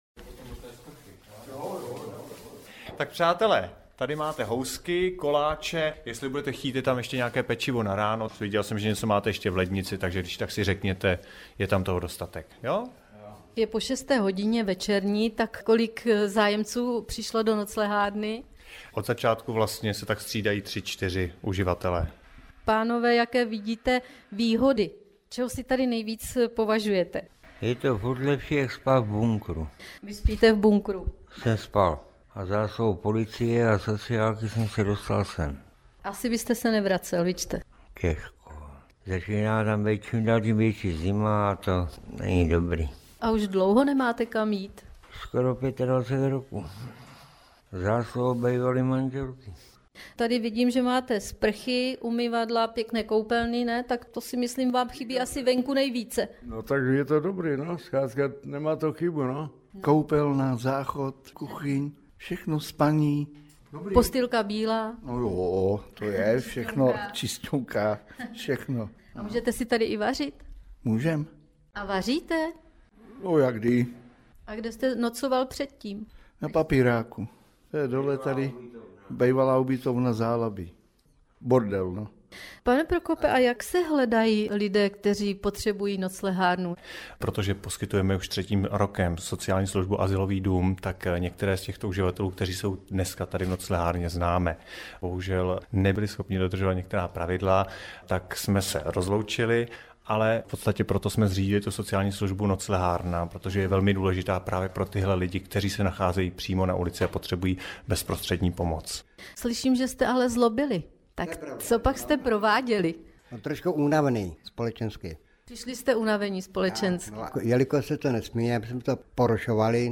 Měla možnost hovořit jak s námi, pracovníky, tak zejména s klienty této služby – s lidmi bez domova.